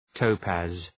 {‘təʋpæz}